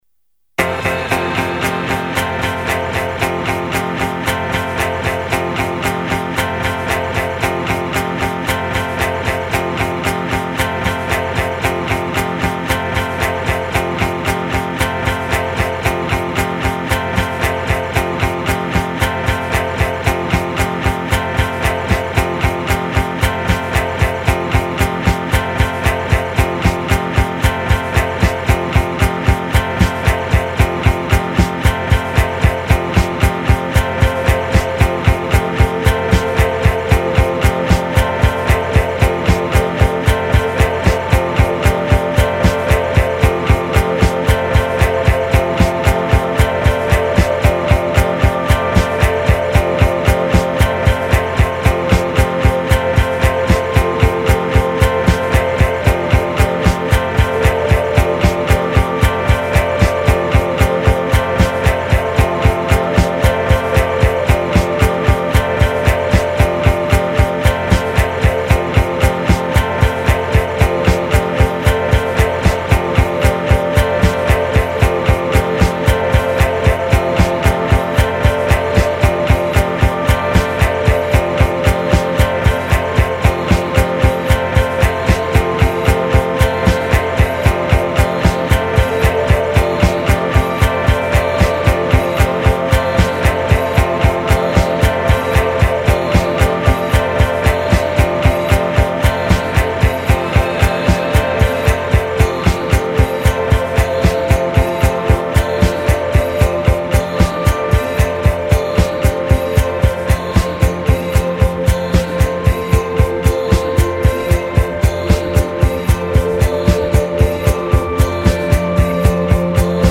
weeeeeeeiiiiiiiiiiiirrrrrrrrrddddddd.